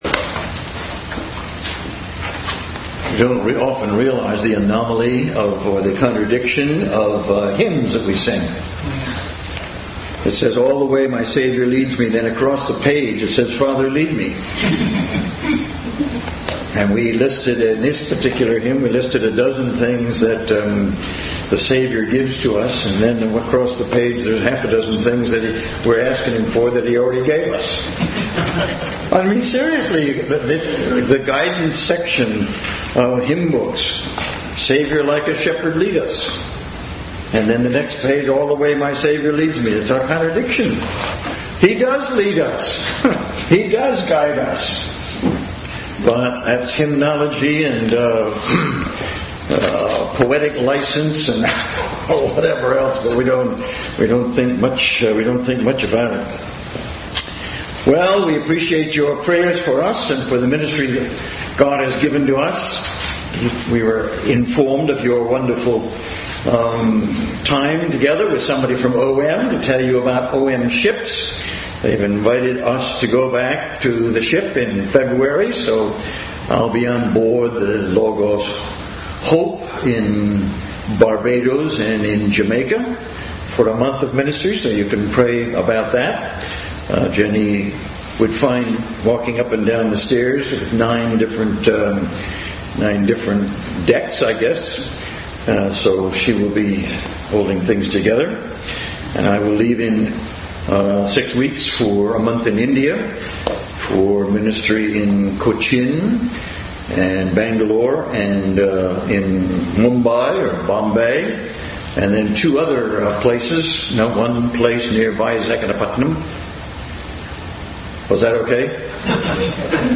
Bible Text: Colossians 1:1-8 | Family Bible Hour messagr.
Colossians 1:1-8 Service Type: Family Bible Hour Bible Text